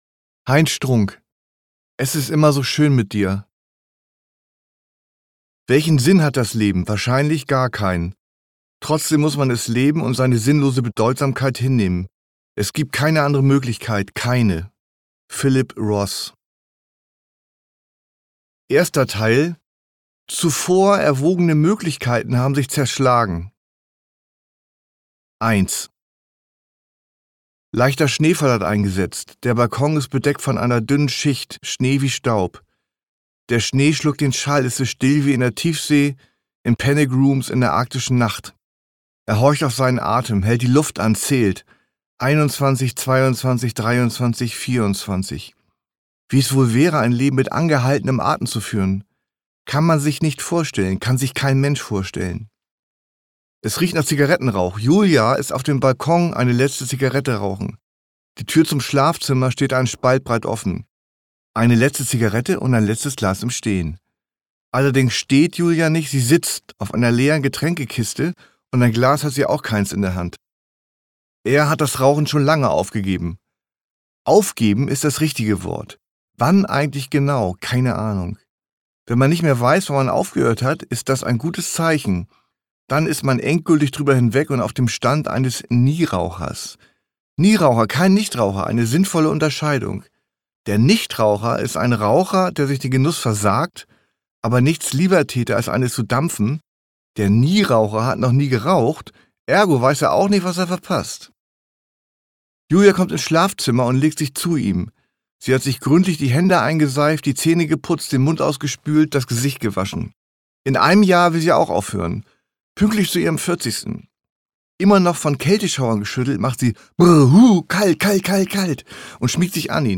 Heinz Strunk (Sprecher)
2023 | 1. Auflage, Ungekürzte Ausgabe